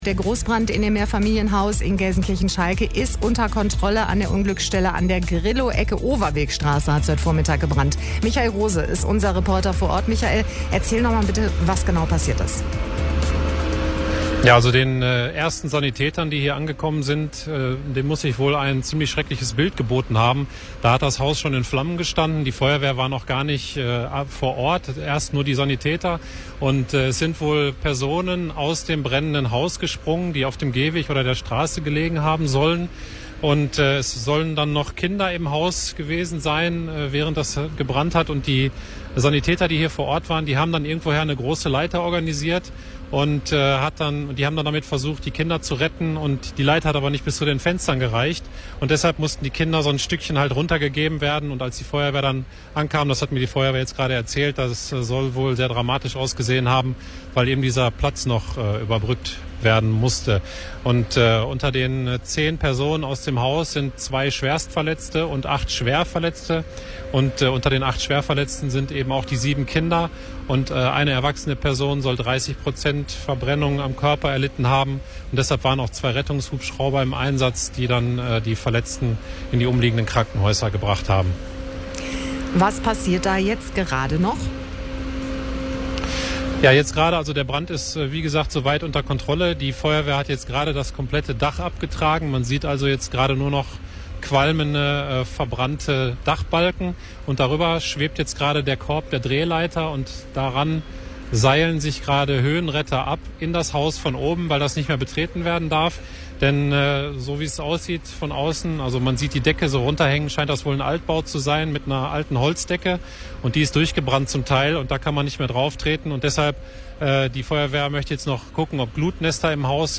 live-schalte-zum-einsatzort-v1.mp3